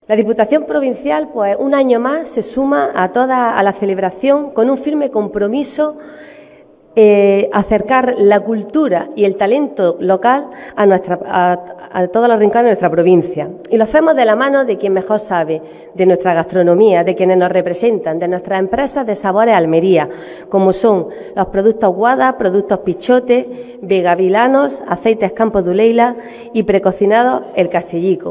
La presentación de este evento se ha llevado a cabo en la Casa Consistorial y la alcaldesa ha estado acompañada por la concejala de Empleo, Comercio, Juventud y Emprendimiento, Lorena Nieto, por el delegado de Empleo de la Junta de Andalucía, Amós García, y  por la diputada provincial de Igualdad y Familia, María Luisa Cruz.